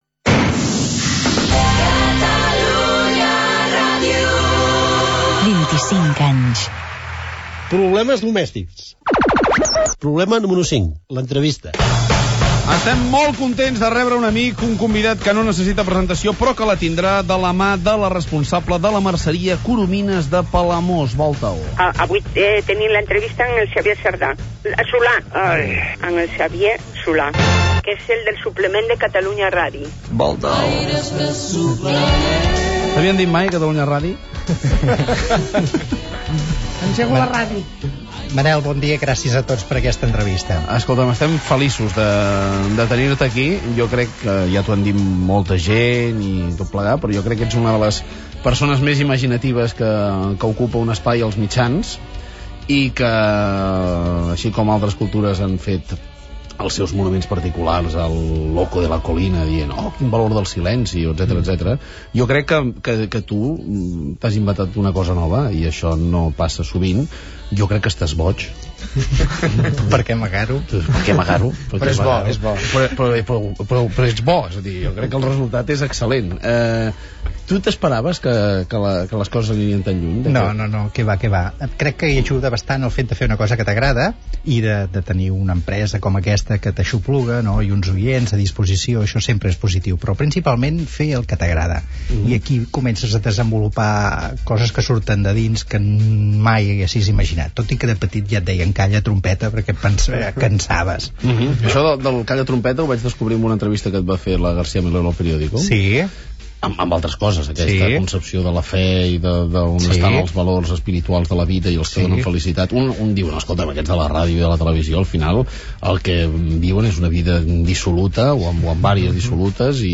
Gènere radiofònic Entreteniment Presentador/a Fuentes, Manel